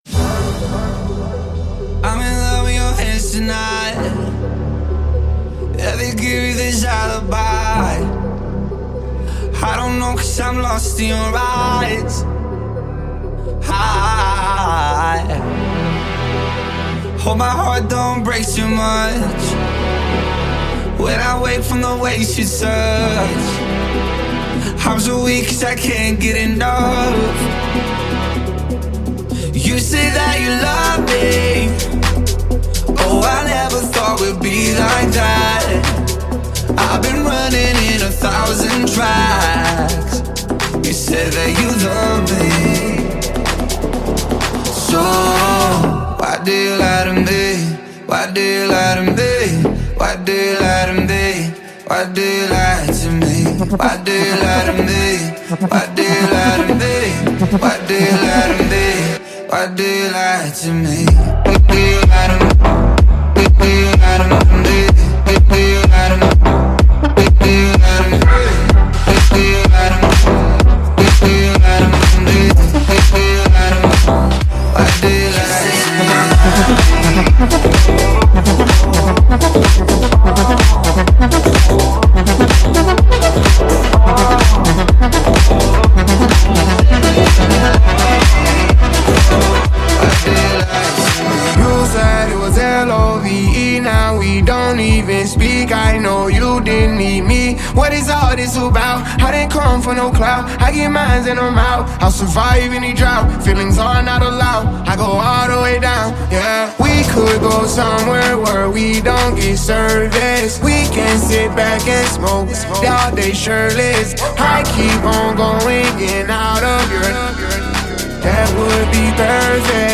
Also find other EDM Livesets, Yearmixes DJ Mixes and